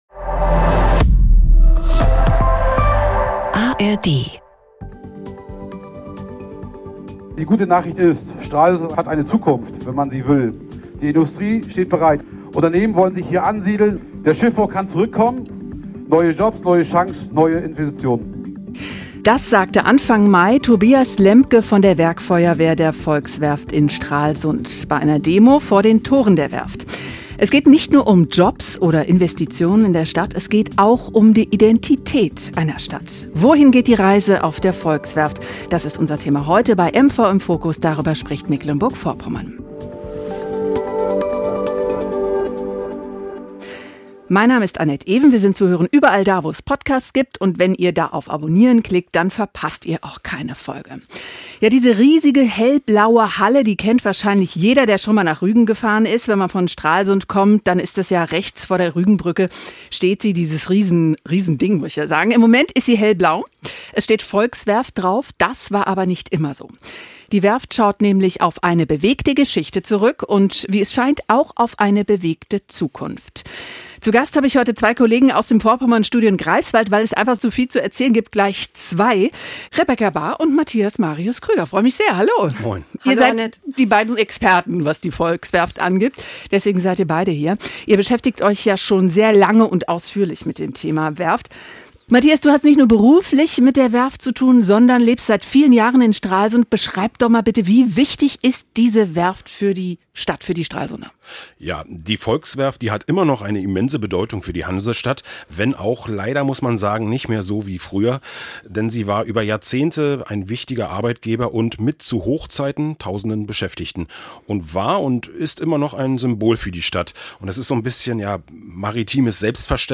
talken dazu wöchentlich im Wechsel mit Reporterinnen und Reportern aus den vier NDR MV Regionalstudios oder mit spannenden Gästen. Im Fokus steht immer ein besonders wichtiges Thema, das direkt vor der Haustür spielt.